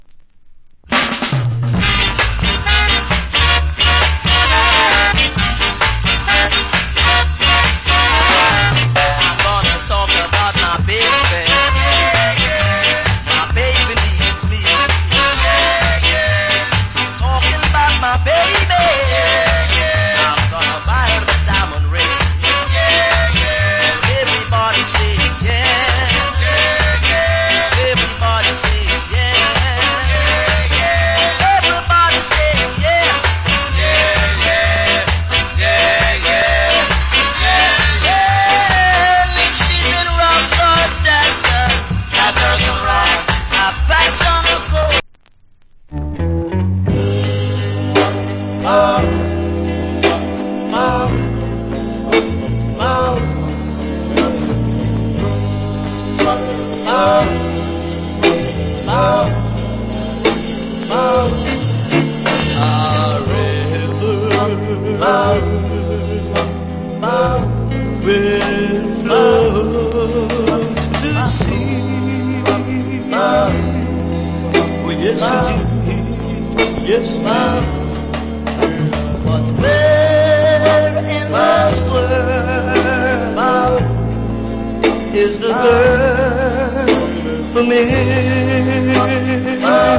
コメント Wicked Ska Vocal. / Sweet & Slow Ballad Vocal.